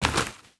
Dirt footsteps 5.wav